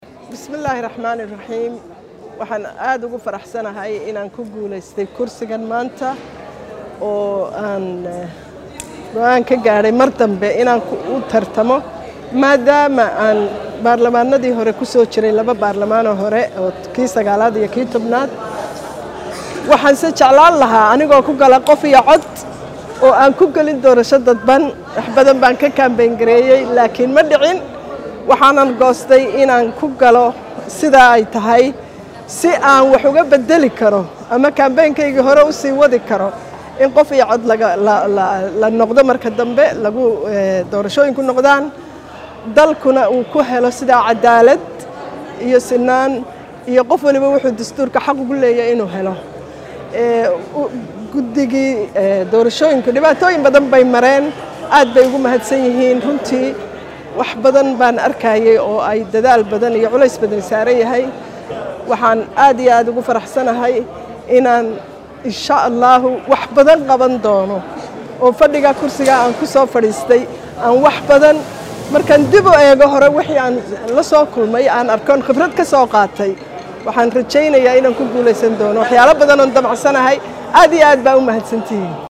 Wasiirkii Hore ee arimaha dibada Soomaaliya Xildhibaan Fowzia yuusuf Xaaji aadan oo Warfidiyeenka kula hadashay Muqdisho ayaa sheegtay in ay aad ugu faraxsantahay in Mar kale loo doorto kursiga ay in mudda ah ku fadhisay.